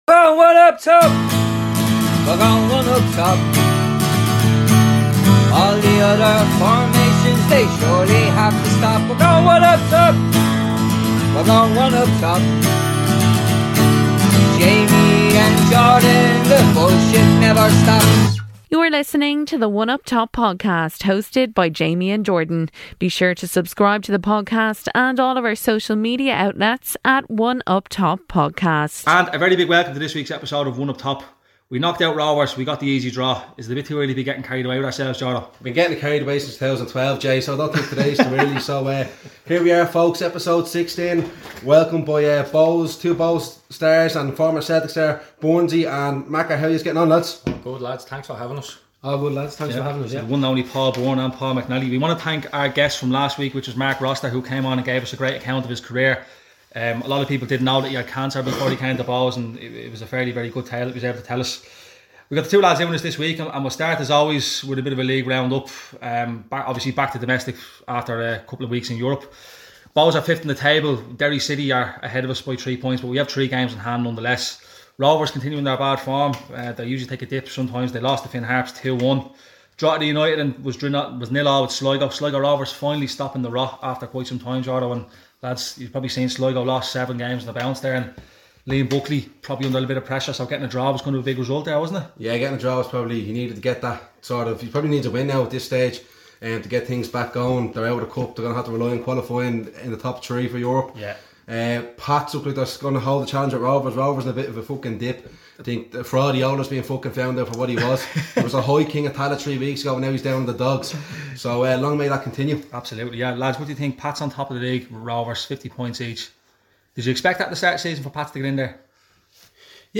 two former Bohs players who talk about their time at the club, with some great stories and tales.